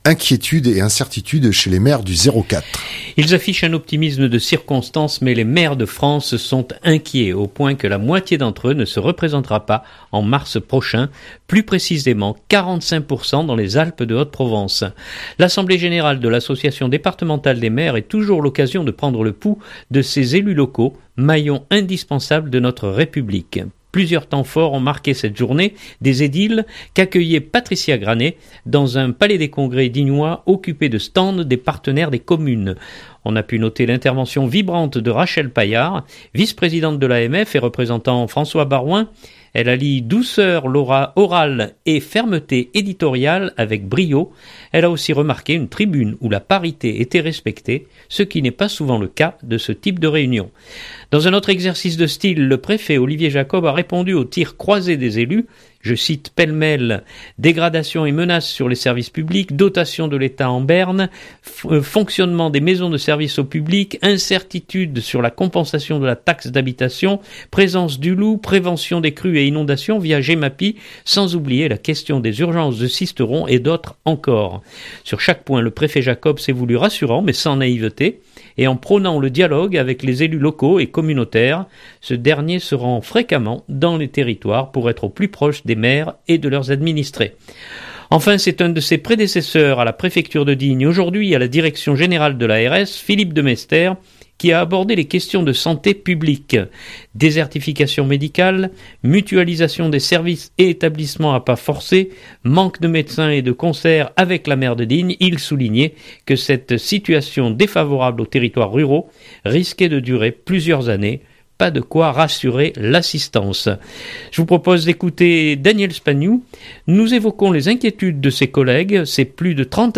Je vous propose d’écouter Daniel Spagnou. Nous évoquons les inquiétudes de ses collègues, ses plus de 30 années passées à animer cette association d’élus locaux et également son cas personnel.